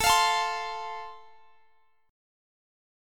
Listen to Am#5 strummed